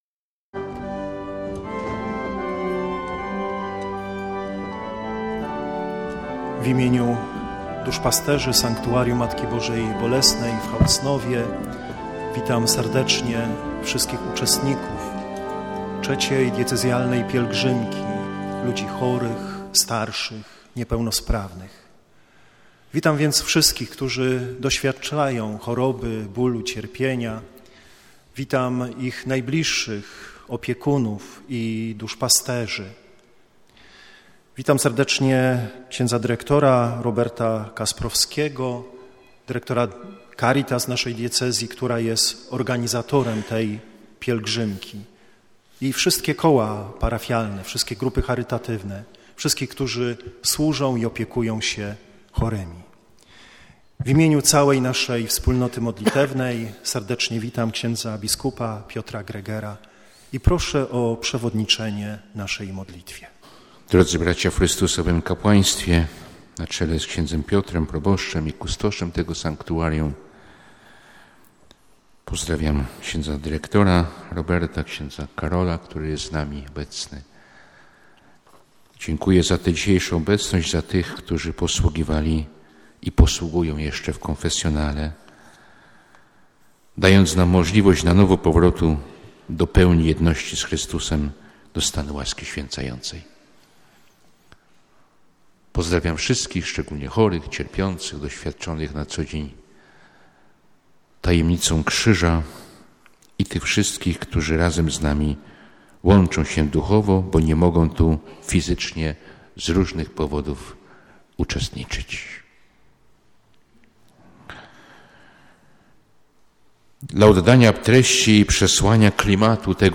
W homilii bp Greger na przykładzie ewangelicznej sceny zwiastowania wyjaśnił, czym jest każde wydarzenie wiary. Jak zaznaczył, sytuacja taka, to swoiste zaproszenie, by zdecydować się na to, co niemożliwe.
halacnow_chorzy_bazylika.mp3